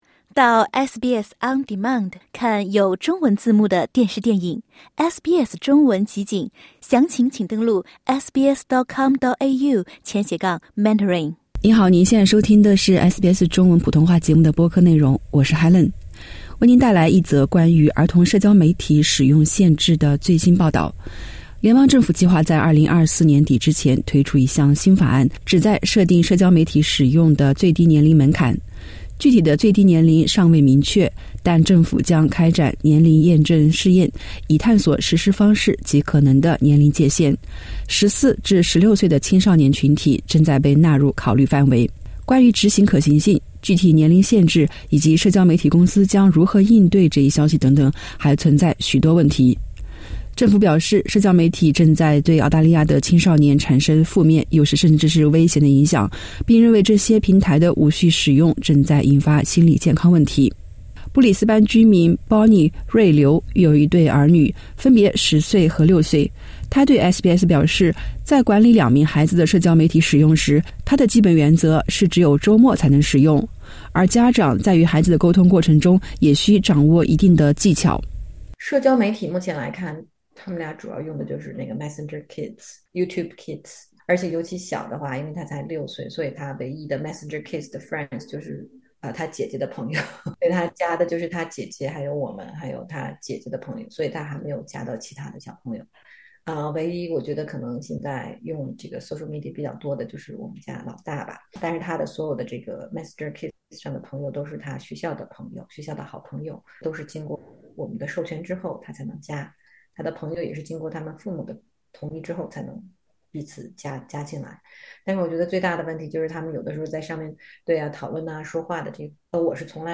点击播放键收听完整采访 家长如何管理儿童的社交媒体使用？